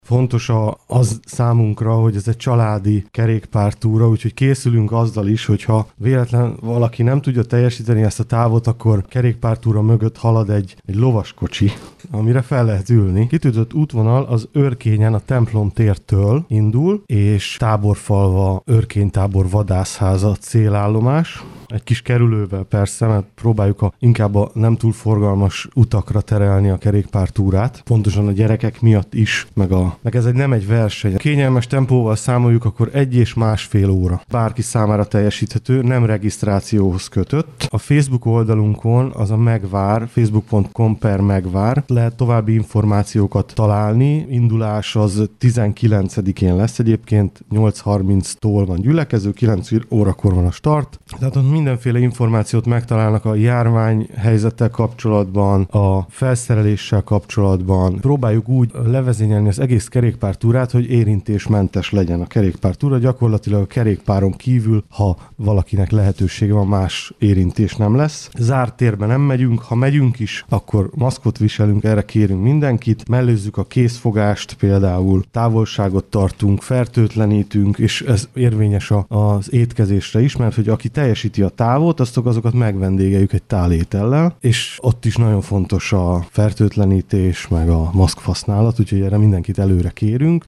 Kőszegi Zoltán polgármester elmondta, milyen ajándékkal készülnek.